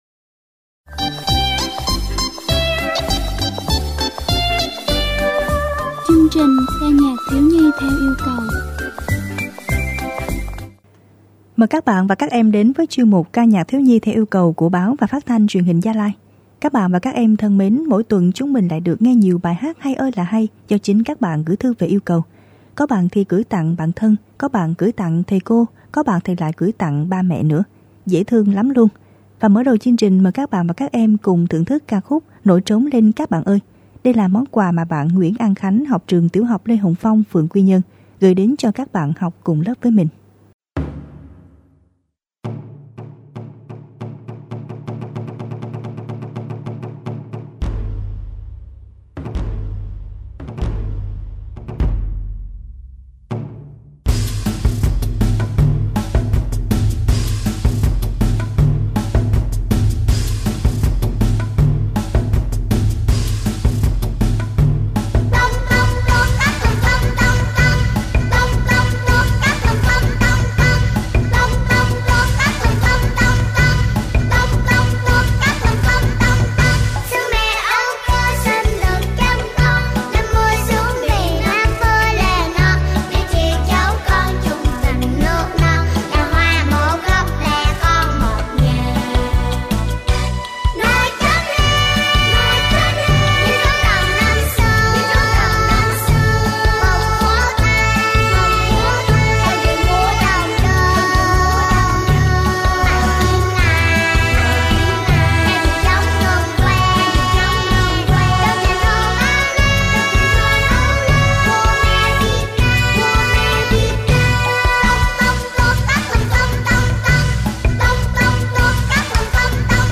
ca nhạc thieu nhi theo yêu cầu